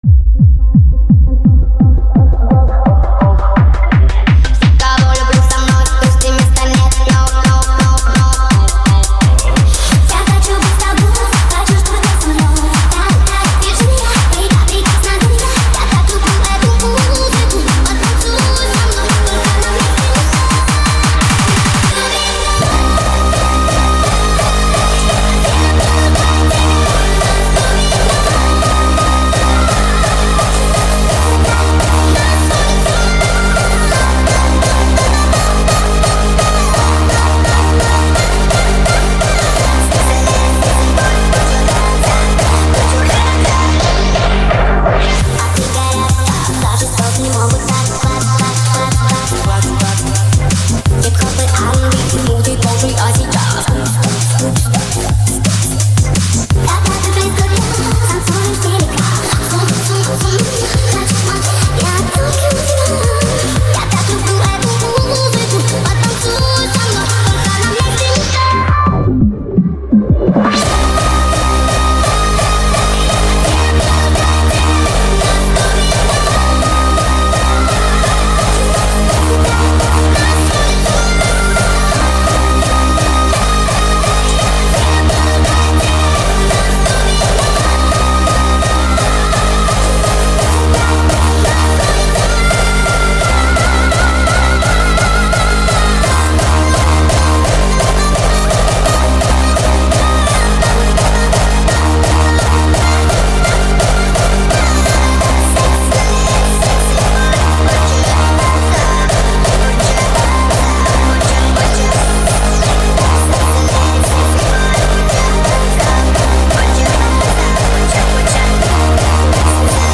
Качество: 320 kbps, stereo
Ремиксы